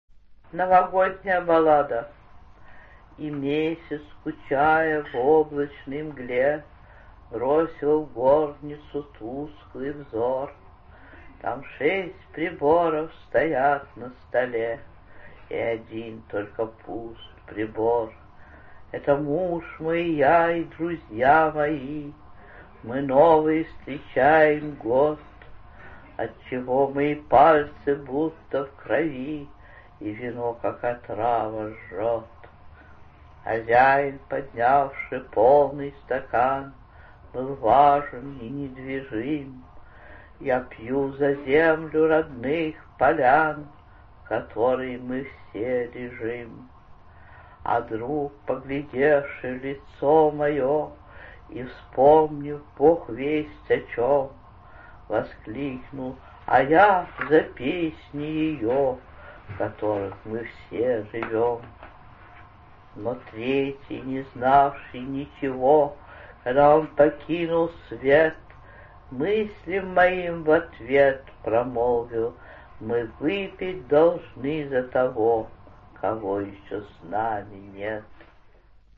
10. «Анна Ахматова – Новогодняя баллада (читает автор)» /
anna-ahmatova-novogodnyaya-ballada-chitaet-avtor